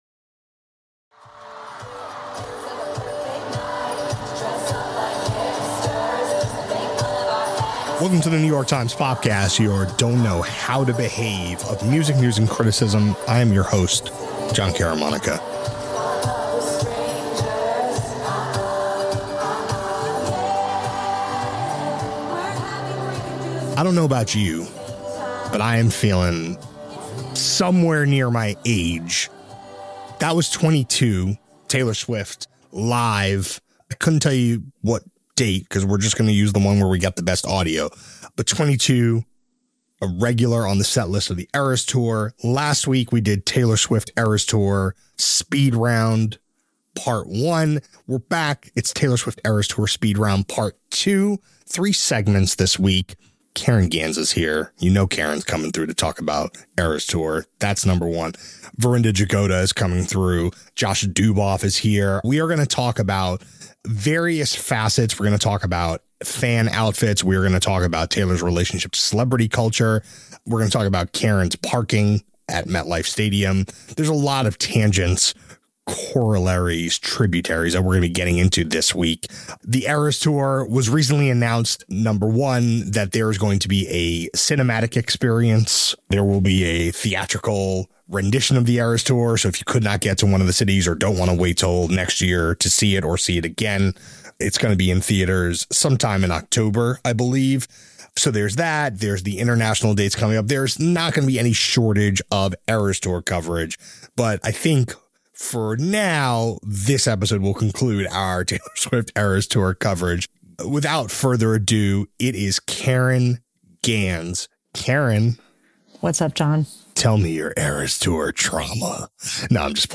A conversation about Swift’s relationship to celebrity, the tour date as destination event and how fans dressed up for the show.